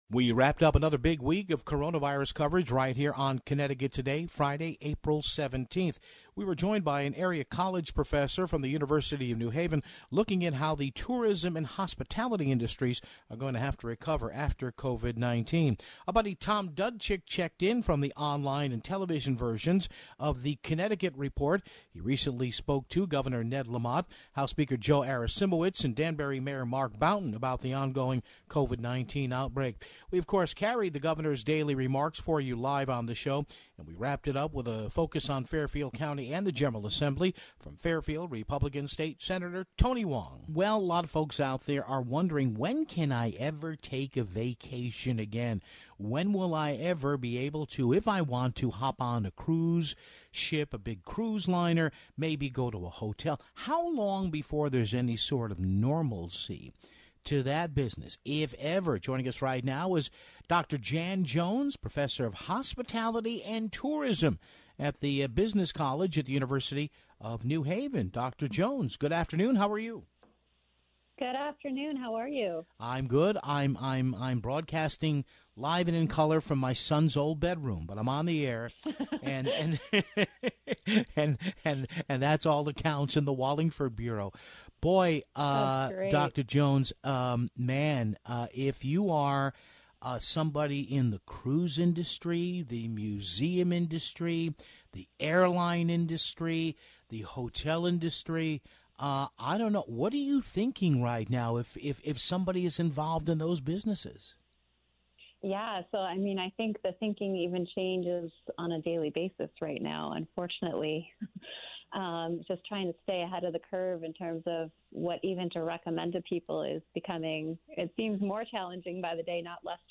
We aired portions of Governor Lamont's daily update. Ending things in style with our friend, Republican State Senator Tony Hwang of Fairfield to give us an update on how things look up at the state capital